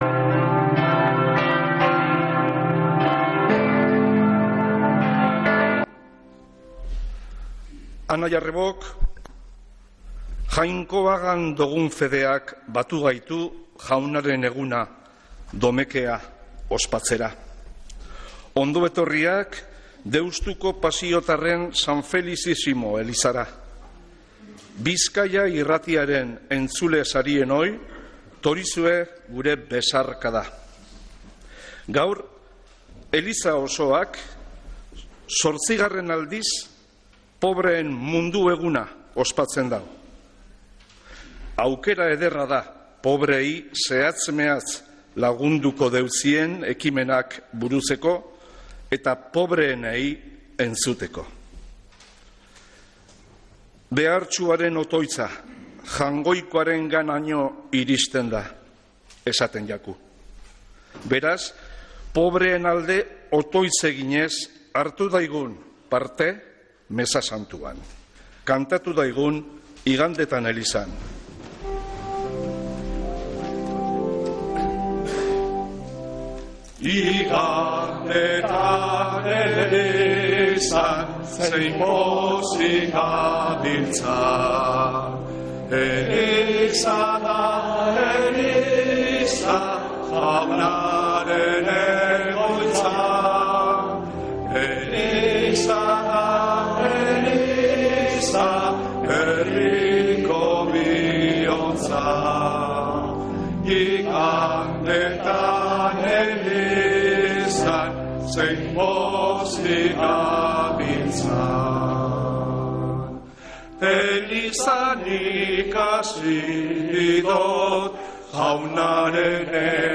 Mezea San Felicisimotik (24-11-17)